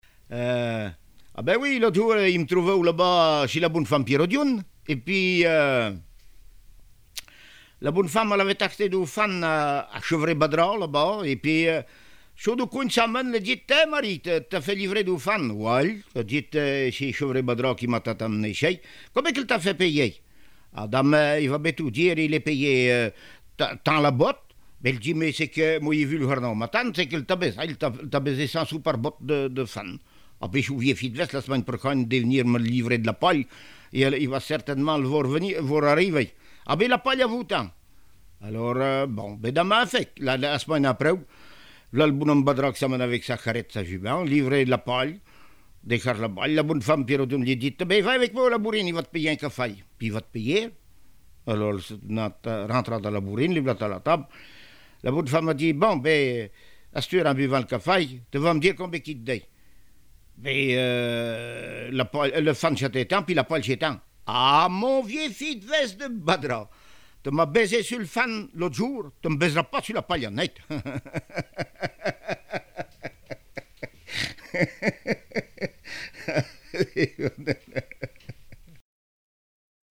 Mémoires et Patrimoines vivants - RaddO est une base de données d'archives iconographiques et sonores.
Genre sketch
Enquête Compagnons d'EthnoDoc - Arexcpo en Vendée
Catégorie Récit